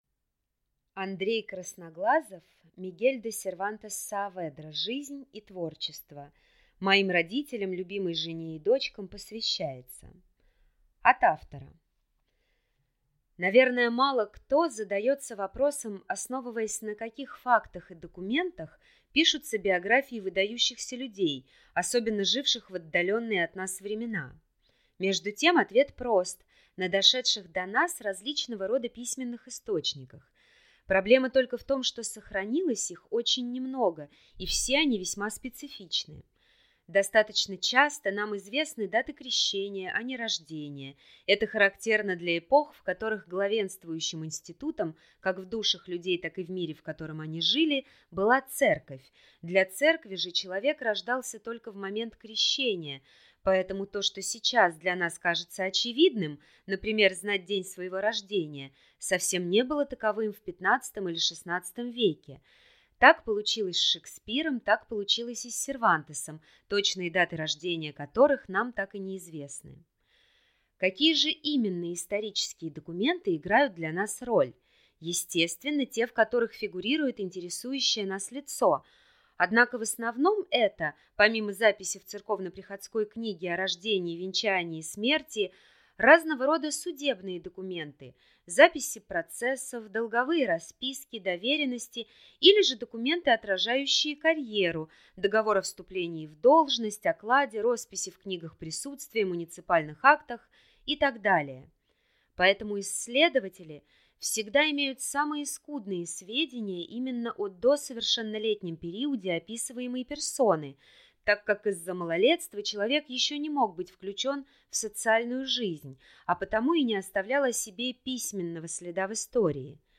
Аудиокнига Мигель де Сервантес Сааведра. Жизнь и творчество | Библиотека аудиокниг